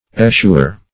Eschewer \Es*chew"er\, n.
eschewer.mp3